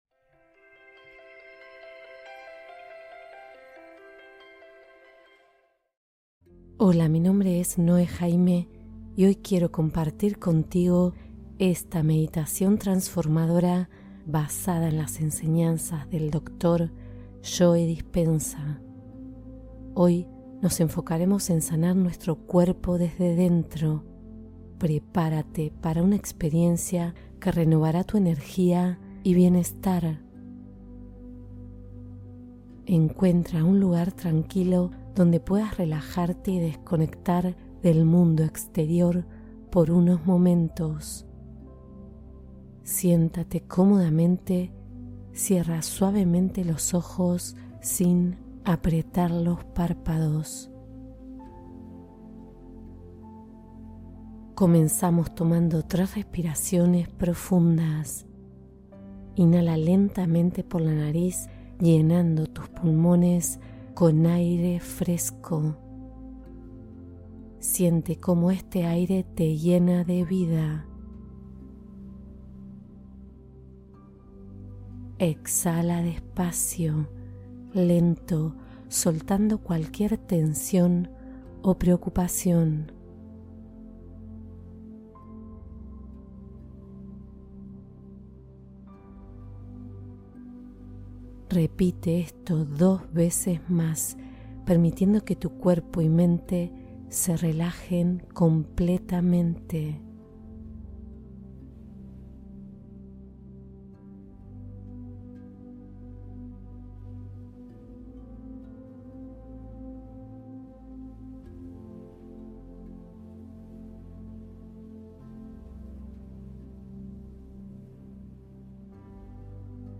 Sana tu mente y cuerpo con esta meditación curativa corta y efectiva